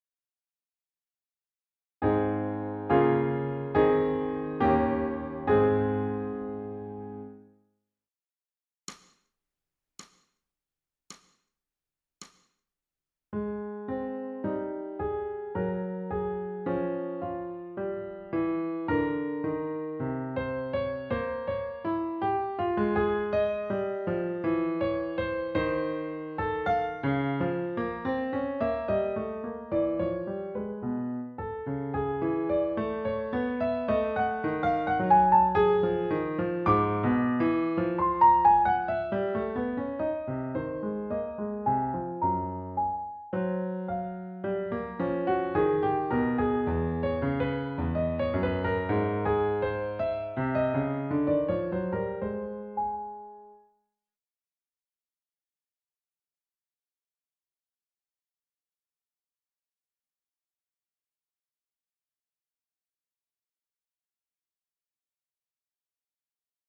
ソルフェージュ 聴音: 2-2-13